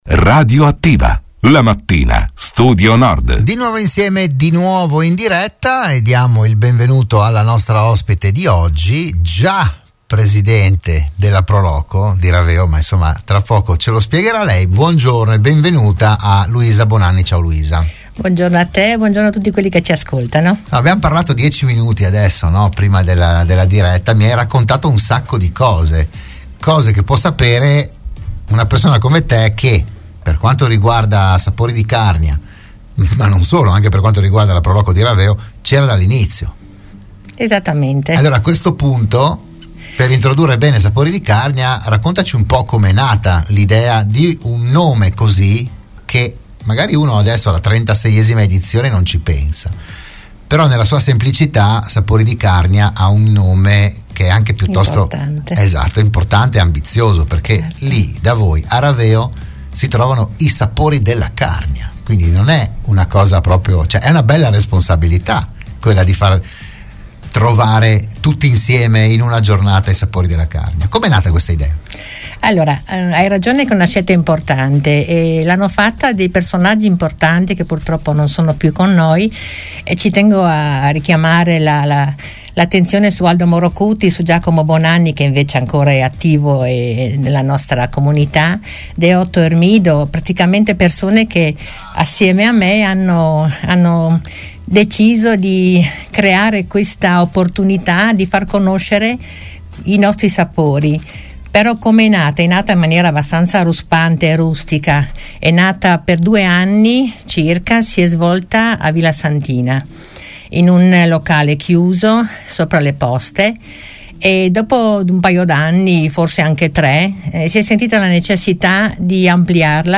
la trasmissione di Radio Studio Nord